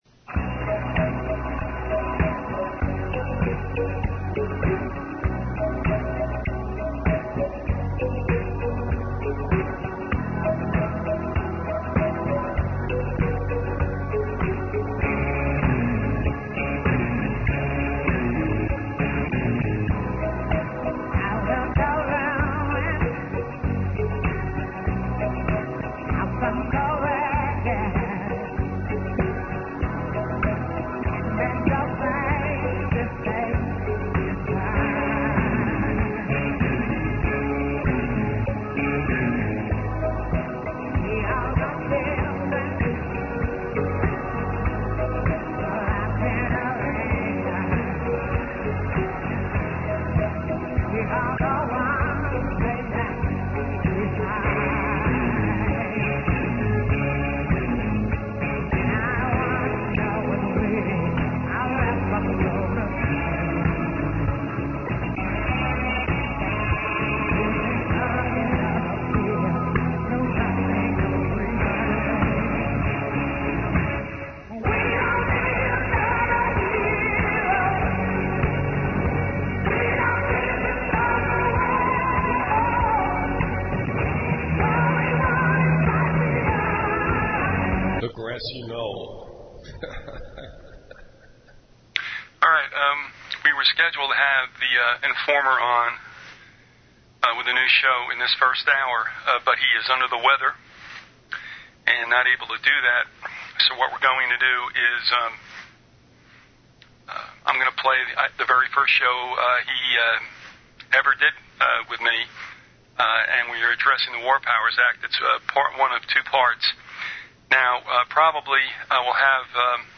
If you took notes and would like to share them with others, please send an email to the archivist and be sure to reference the title of the interview.